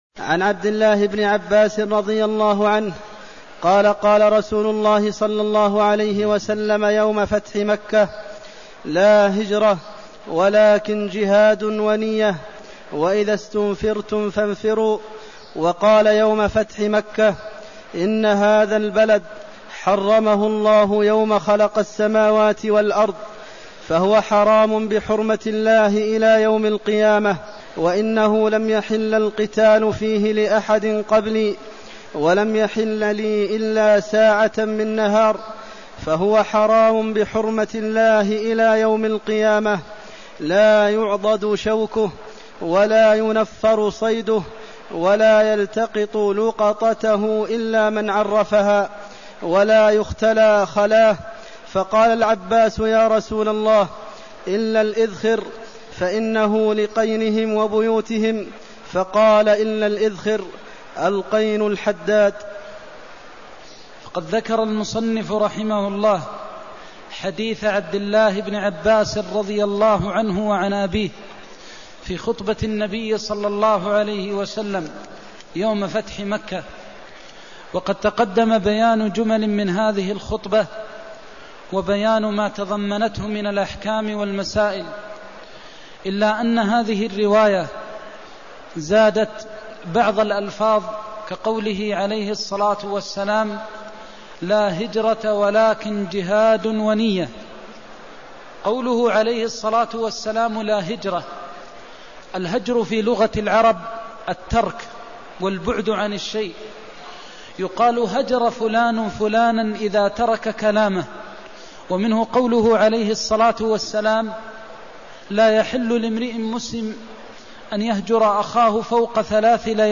المكان: المسجد النبوي الشيخ: فضيلة الشيخ د. محمد بن محمد المختار فضيلة الشيخ د. محمد بن محمد المختار لا هجرة بعد الفتح (210)